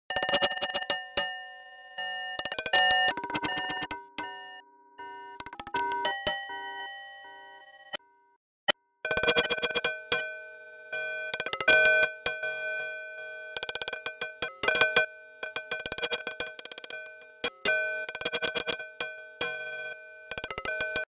Als dritten Rhythmus-Kandidaten füge ich noch Sugar Bytes Cyclop hinzu, einen Hybrid-Synthesizer mit ausgesprochen kernigem Klang: